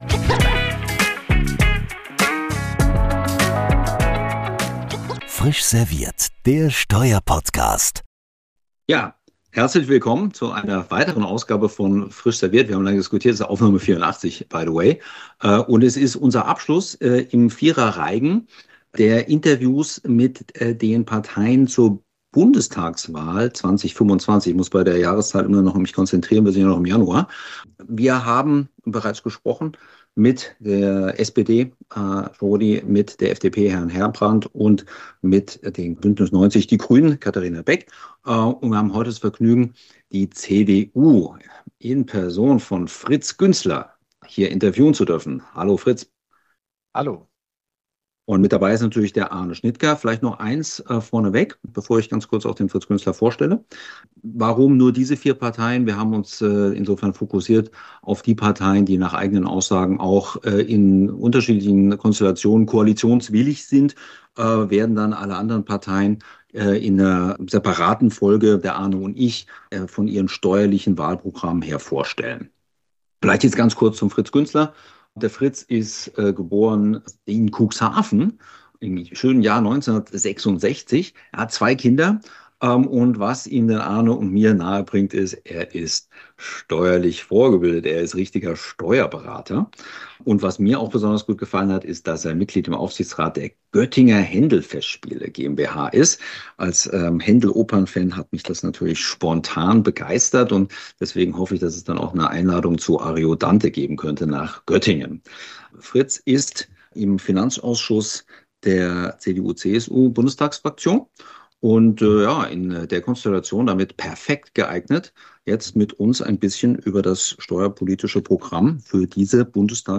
In dieser vierten Folge ist Fritz Güntzler von der Bundestagsfraktion CDU/CSU, Mitglied des Finanzausschusses, zu Gast. Nach der Frage, ob der Staat oder die Privatwirtschaft primär zu Investitionen angeregt werden soll, wird dargestellt, welche Maßnahmen im Bereich der Unternehmenssteuern zur Stärkung des deutschen Standorts angedacht sind und welchen Stellenwert das Thema „Decluttering“ einnimmt.